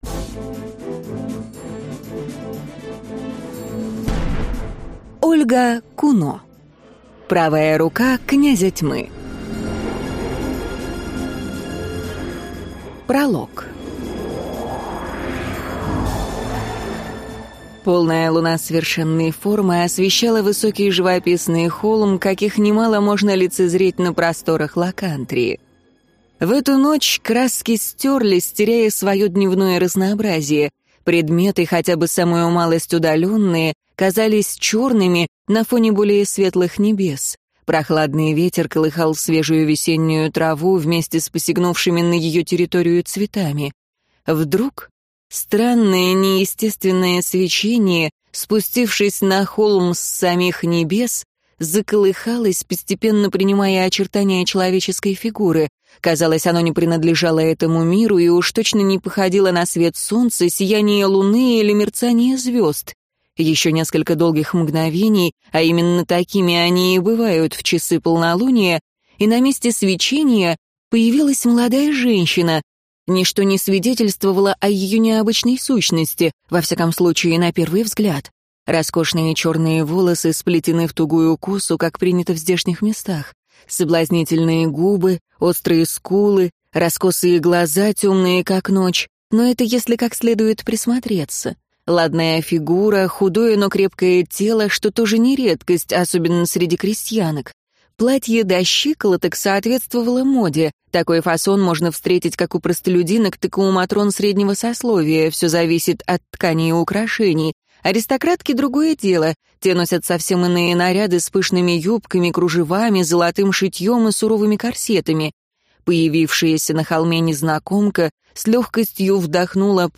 Аудиокнига Правая рука князя Тьмы | Библиотека аудиокниг